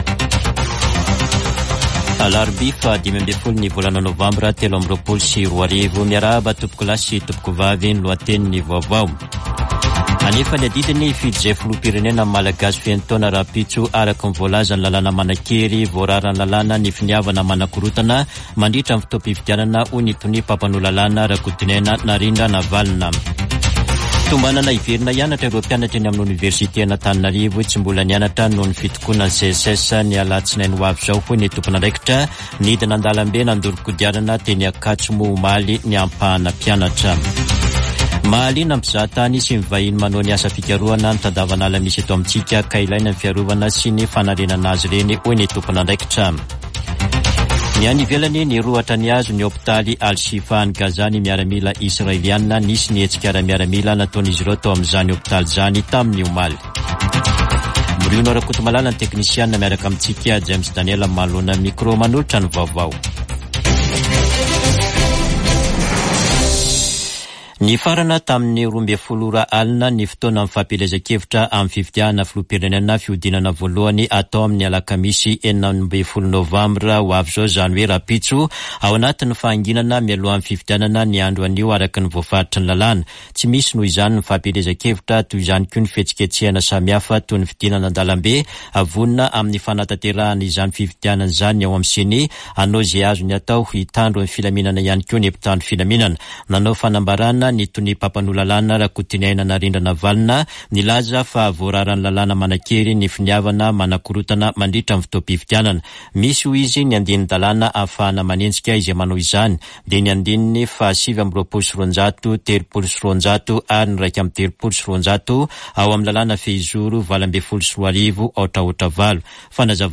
[Vaovao maraina] Alarobia 15 nôvambra 2023